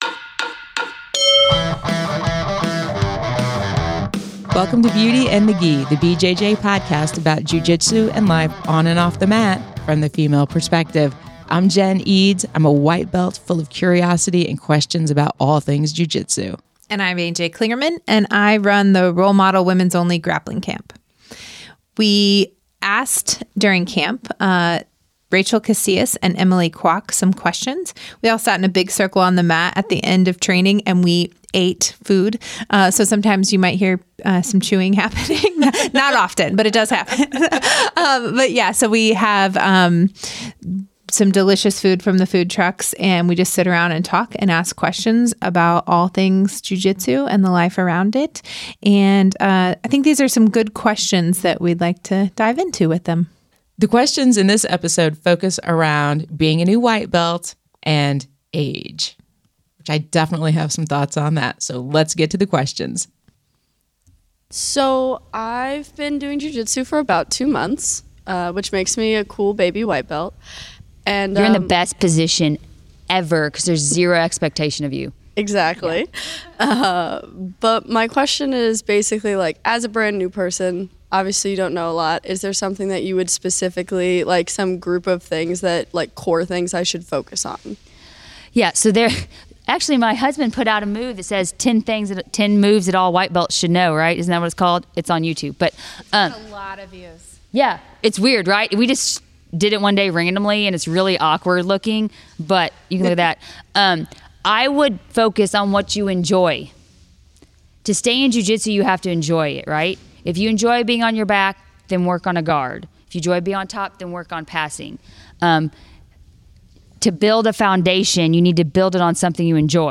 Questions for this episode were recorded live during the Q&A session at the 2019 Roll Model Camp in Indianapolis.